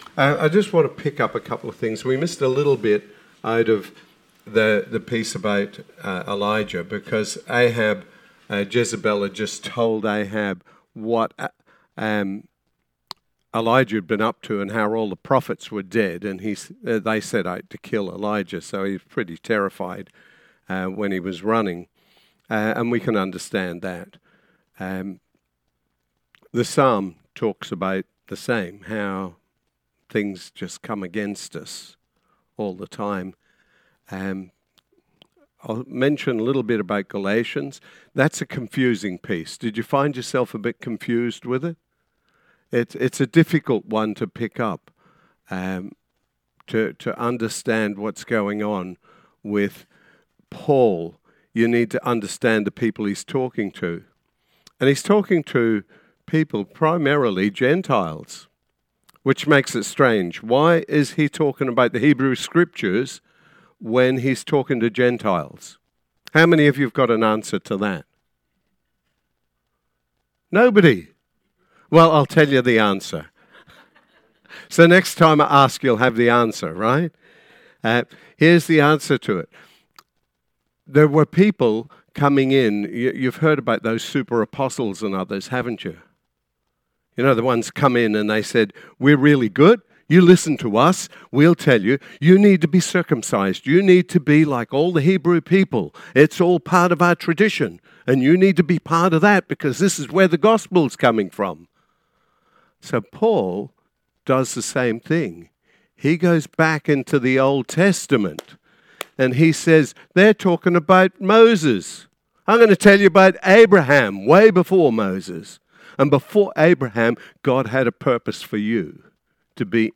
Sermon 22nd June 2025 – A Lighthouse to the community